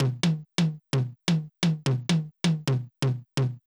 CLF Beat - Mix 13.wav